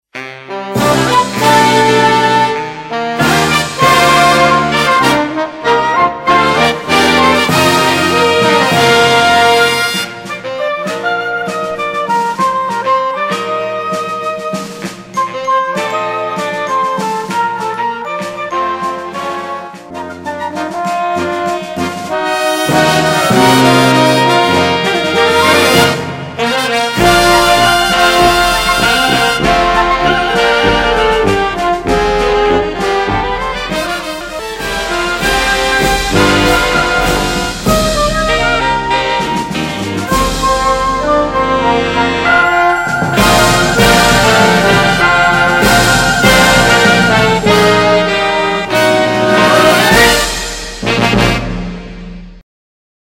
難易度 分類 並足９８ 時間 ３分０５秒
編成内容 大太鼓、中太鼓、小太鼓、シンバル、トリオ 作成No ２７０